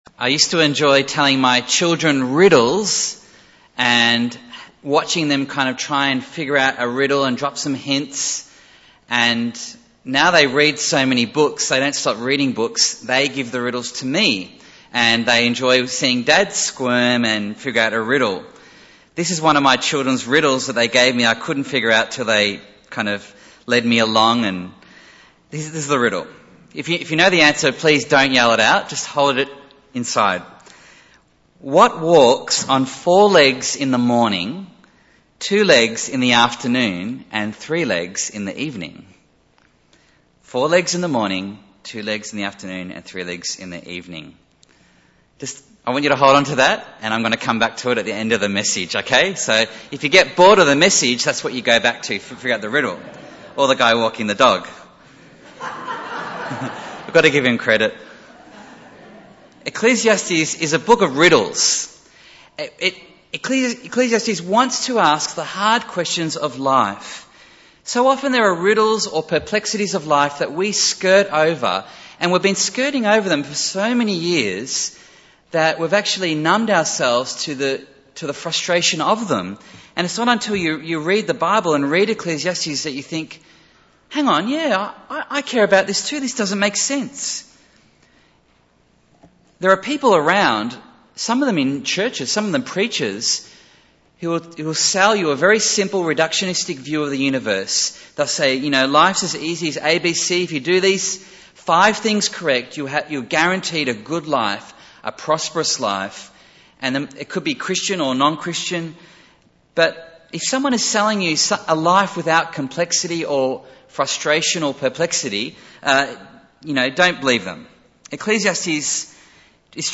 Bible Text: Ecclesiastes 9:1-16 | Preacher